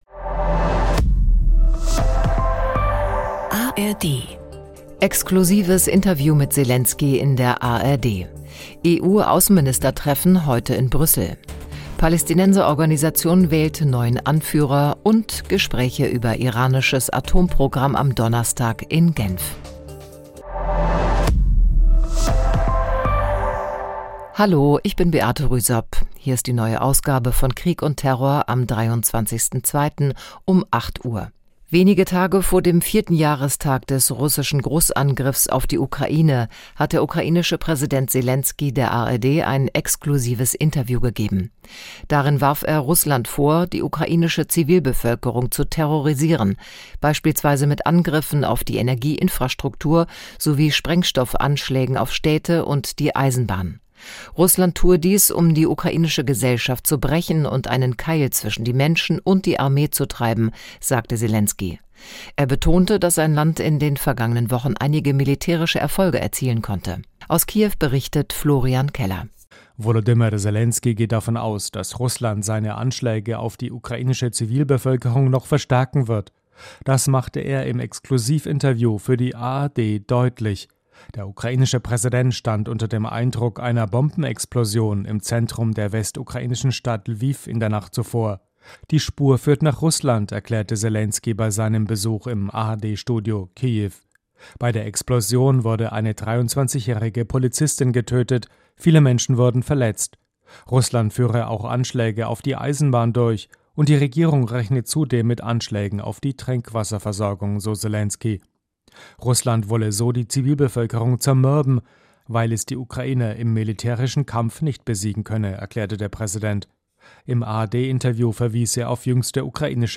Interview mit Selensky in der ARD